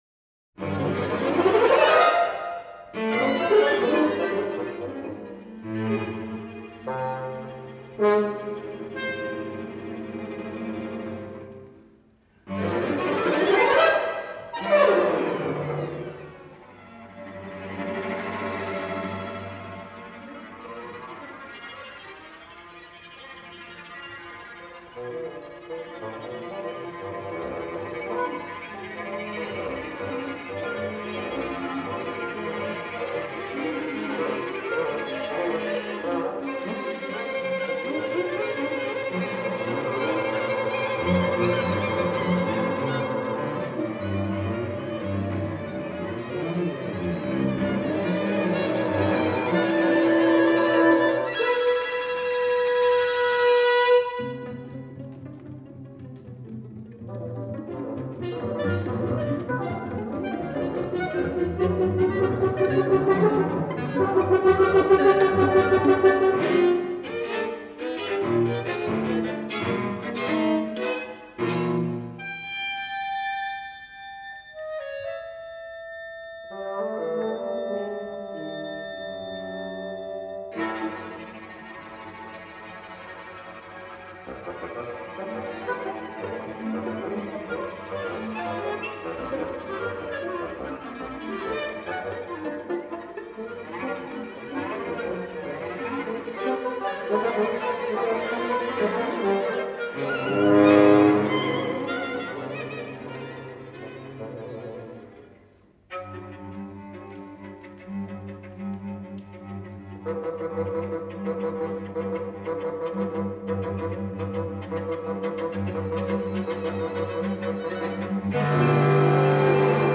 Septeto Op.45 para cuarteto de cuerdas, clarinete, corno y fagot